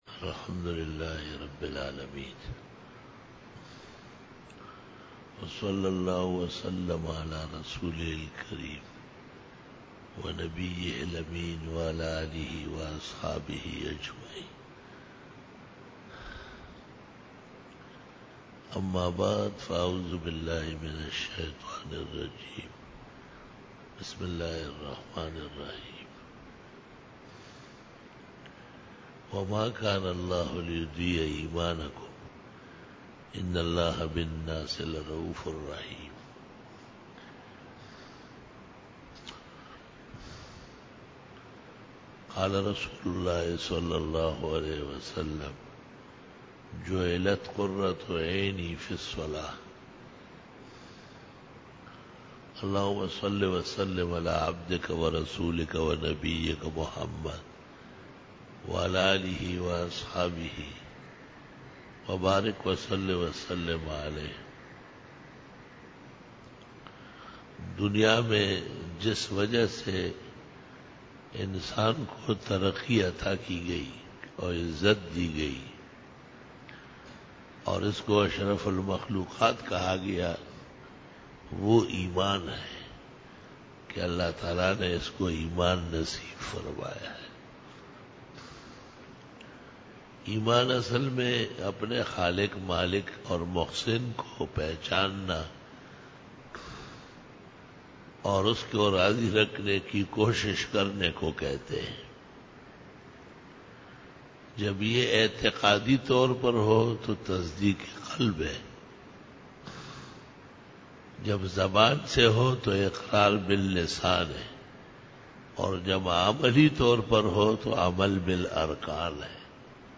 بیان جمعۃ المبارک
Khitab-e-Jummah